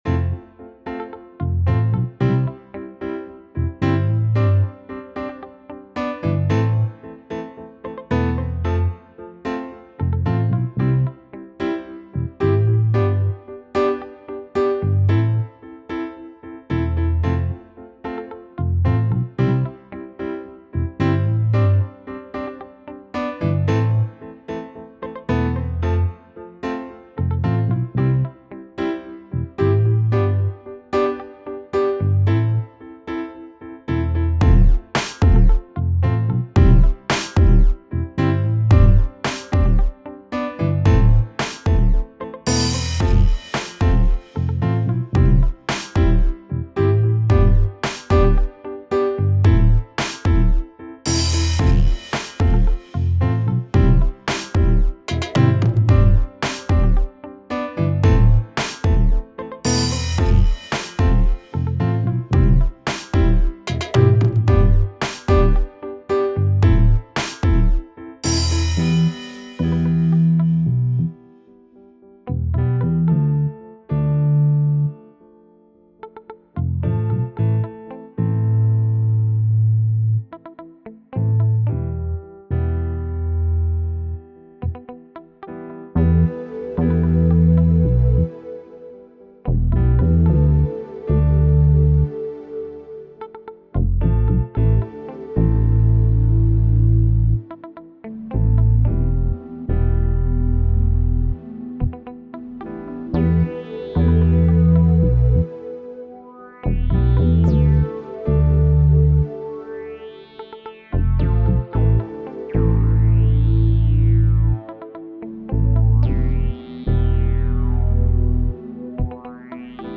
2 beats
sound like from different songs